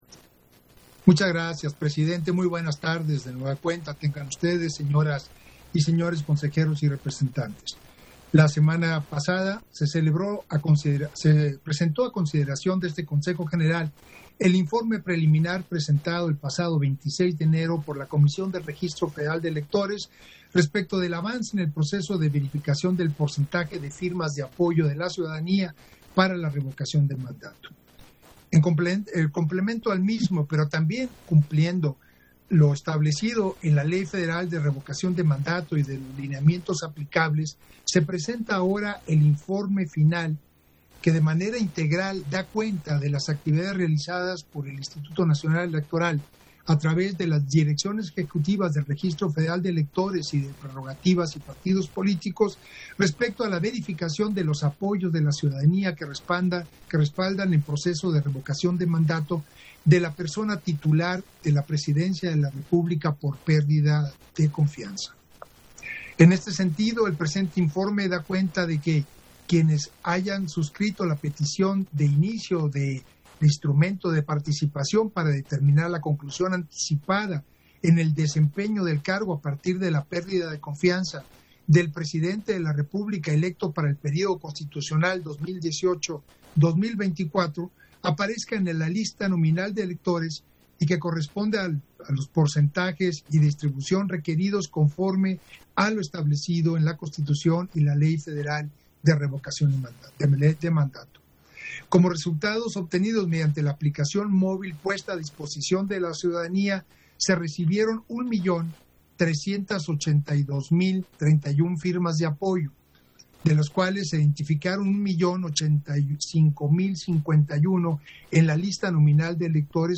Intervención de Edmundo Jacobo Molina, en Sesión Extraordinaria, relativo al informe final respecto del proceso de verificación de firmas de apoyo para la Revocación de Mandato